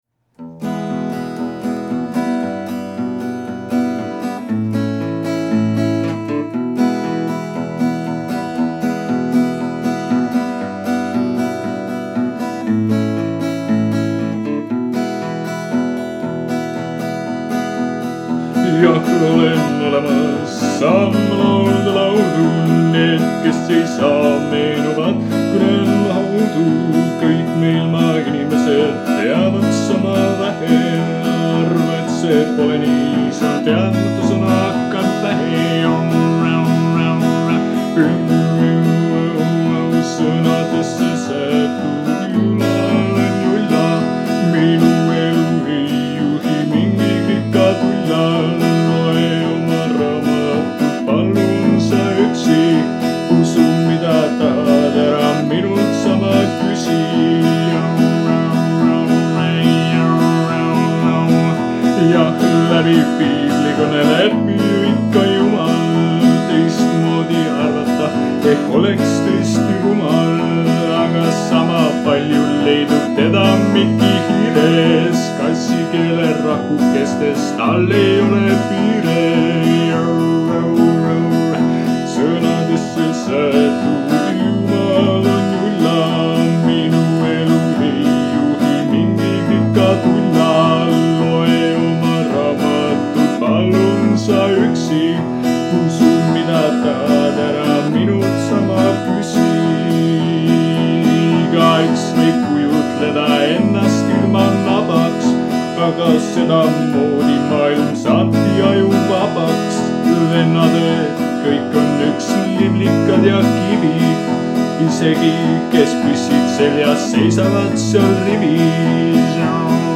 Veiderdav-pühaliku tooniga laul, mis võtab vast päris hästi kokku viimaste kuude mõtteveeretused.